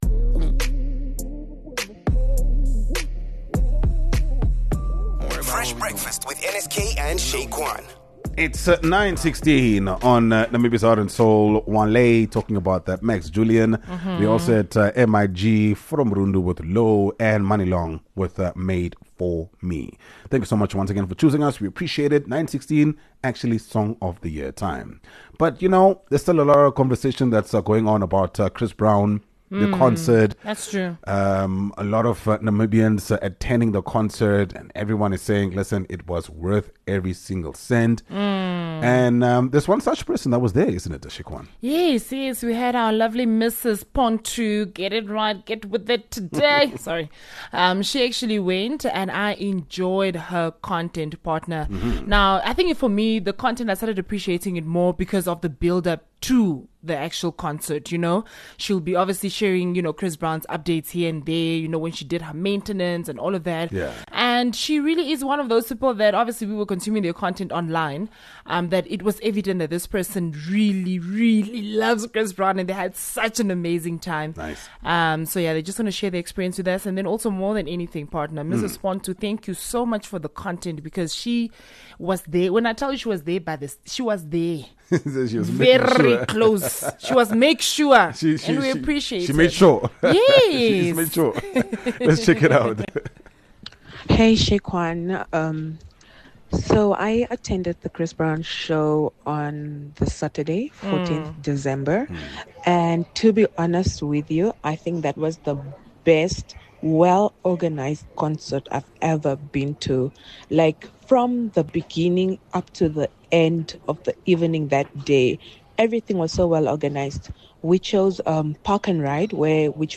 17 Dec Reporting LIVE from FNB Stadium